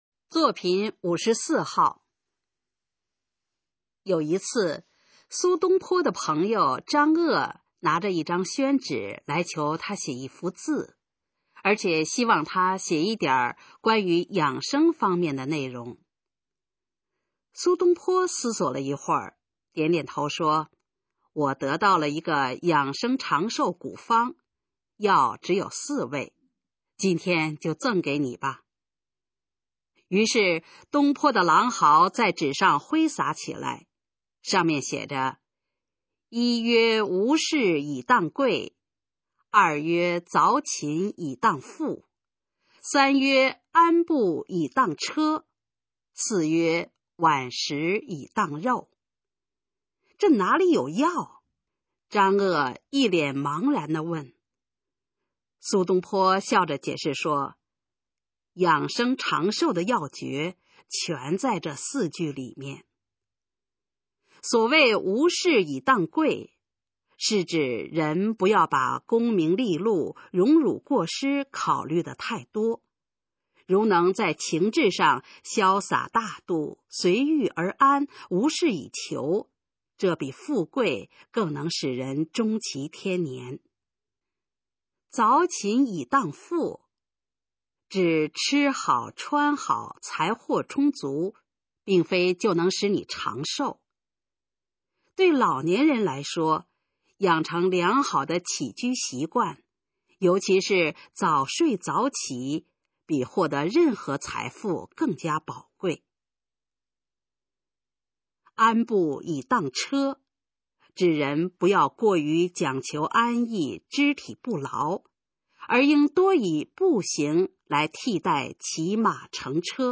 首页 视听 学说普通话 作品朗读（新大纲）
《赠你四味长寿药》示范朗读